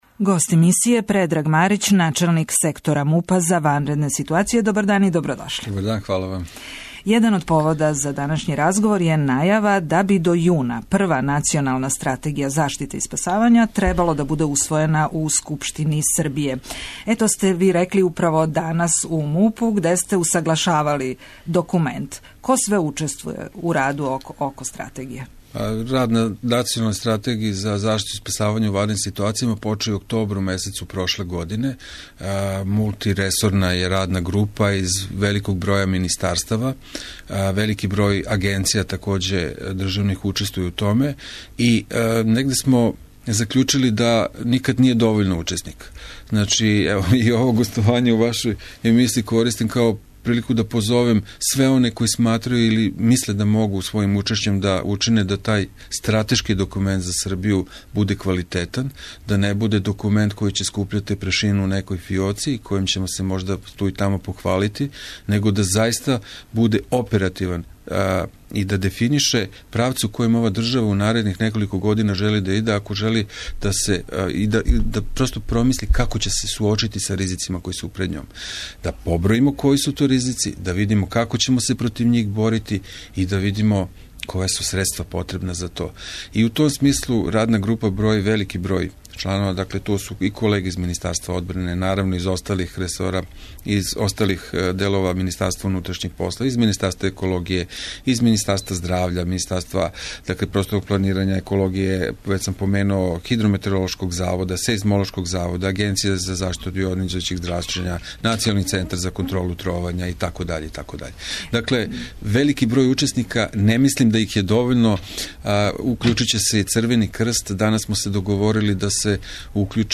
Национална стратегија за ванредне ситуације наћи ће се пред посланицима Скупштине Србије до јуна, најавио је гост емисије Предраг Марић, начелник Сектора МУП-а Србије за ванредне ситуације.